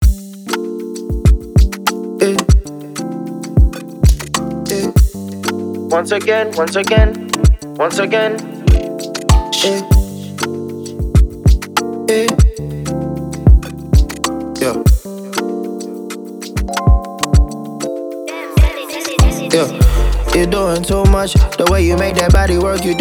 Hip-hop and Rap